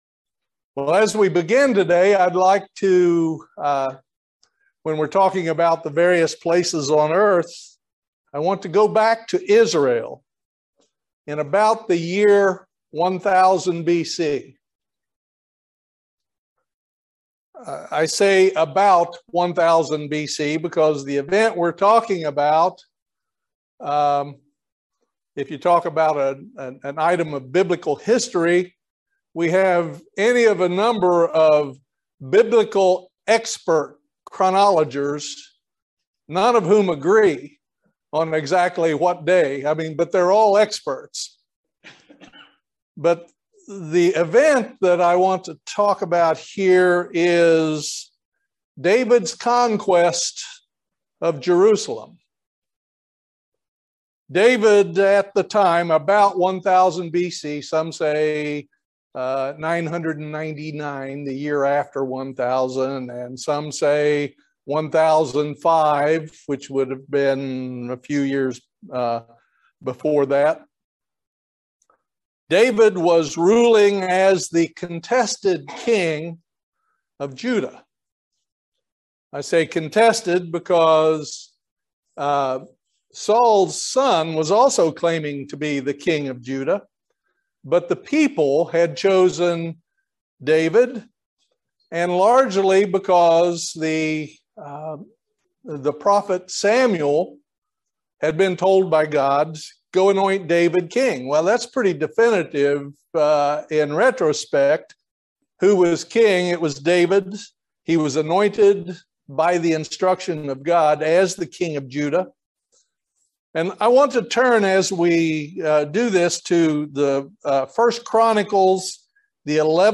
Join us for this excellent video sermon. Satan puts stumbling blocks in front of us constantly. We have to over come these , and press forward toward our goal of being in God's kingdom.
Given in Lexington, KY